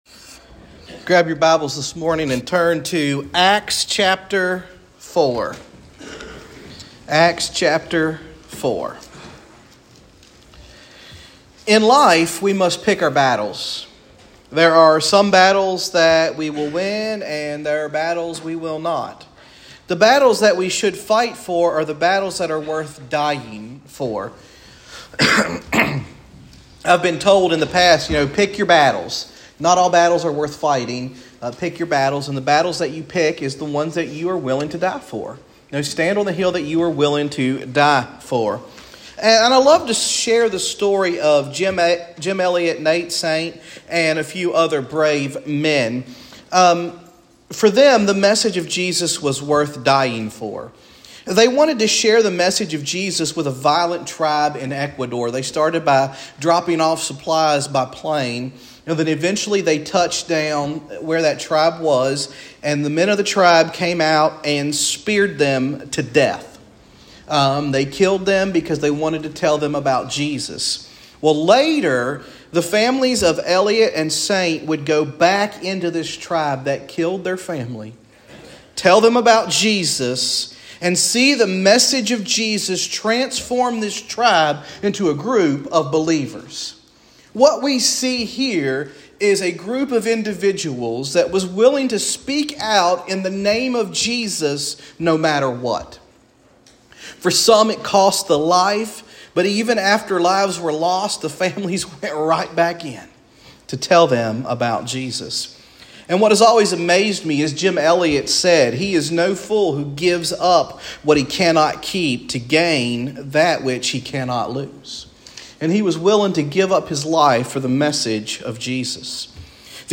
Sermons | Hopewell First Baptist Church
Guest Speaker